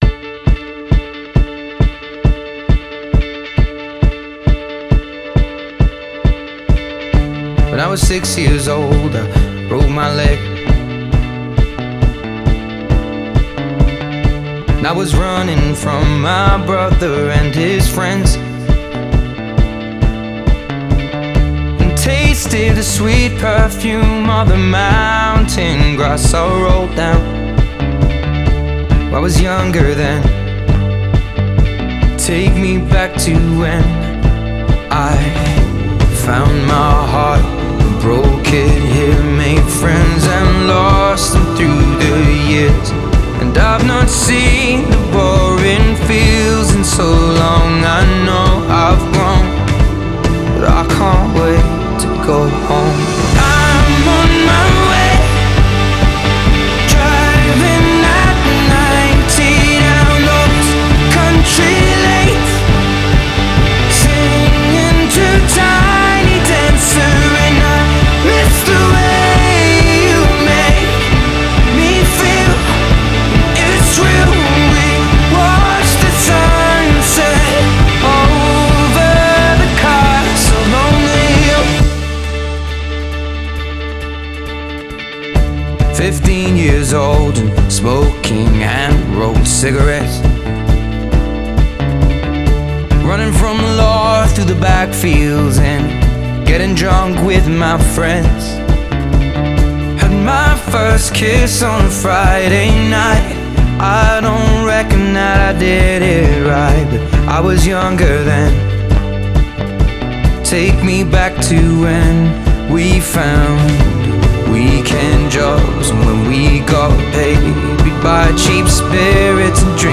Pop / Singer-Songwriter